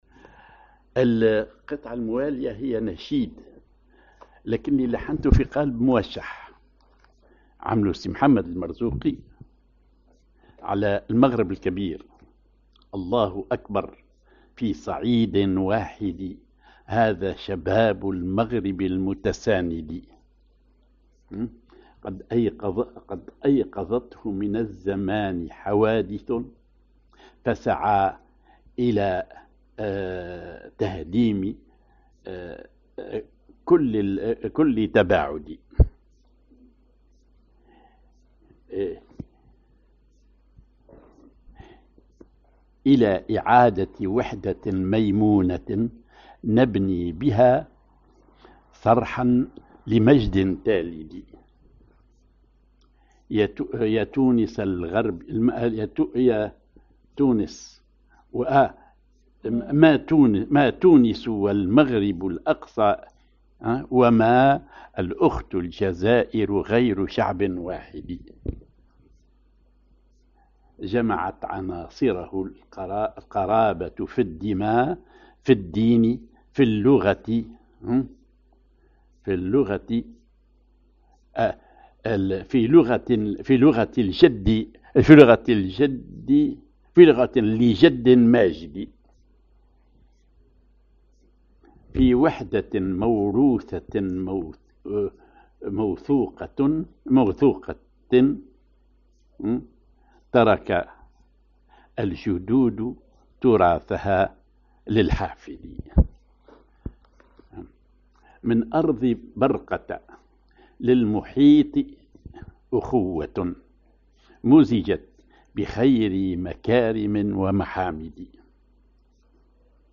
ar حجاز
ar الدور هندي